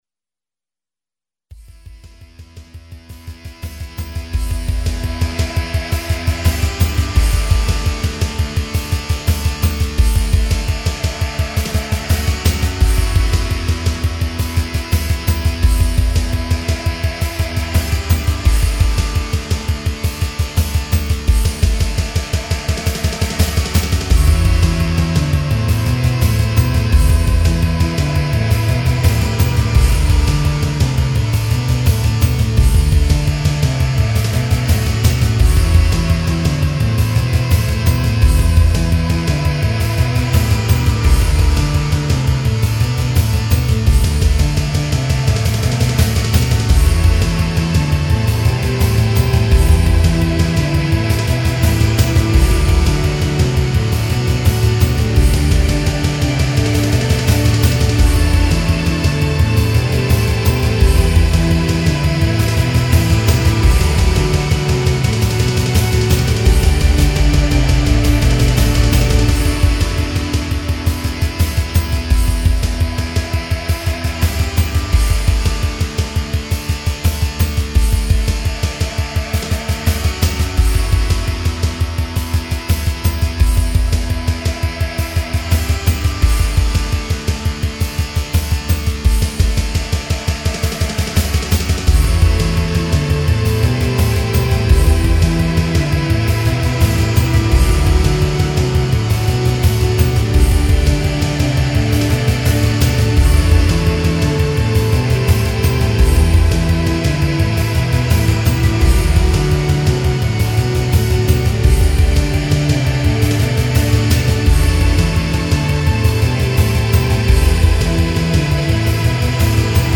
Rock
'05 Remix EQ